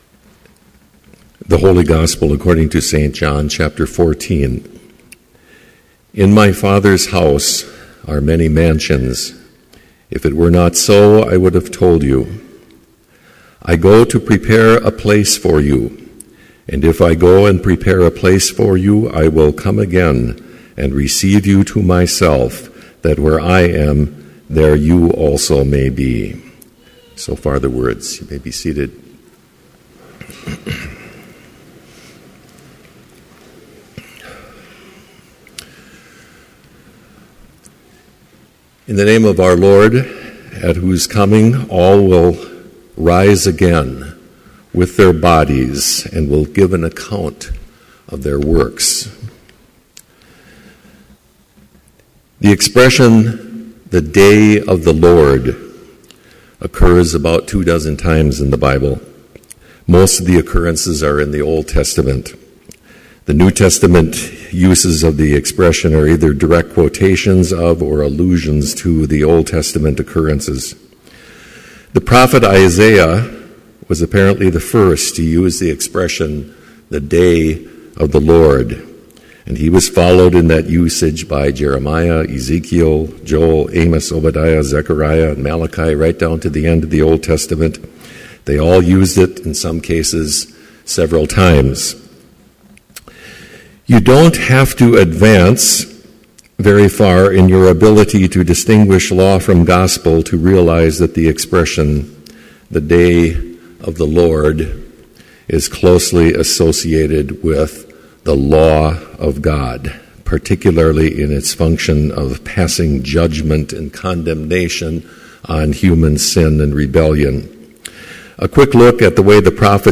Complete Service
• Prelude
• Homily
• Postlude